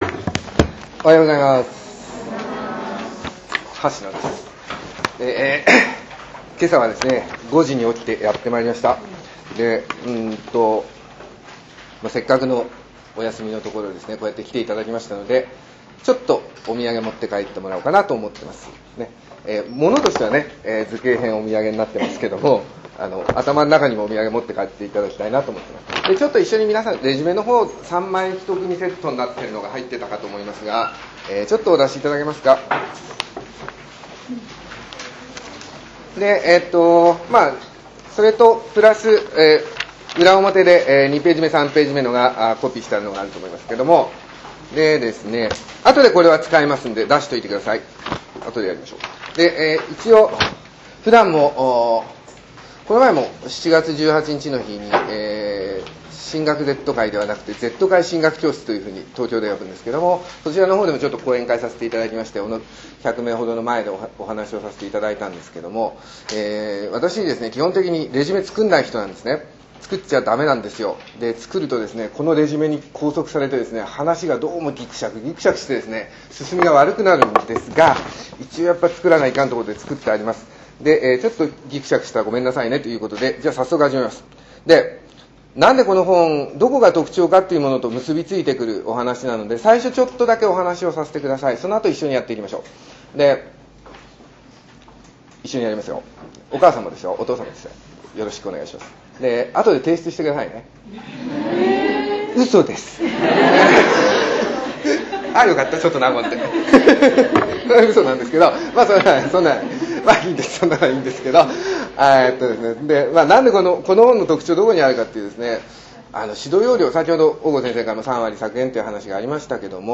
先日大阪で行った「特別授業」の音声をもらいました．全部だとファイルサイズが大きすぎるので，さわりの「挨拶」部分のみなんですけど，アップしてみました．いらっしゃれなかった皆様には，雰囲気だけでも味わっていただけるかと思います．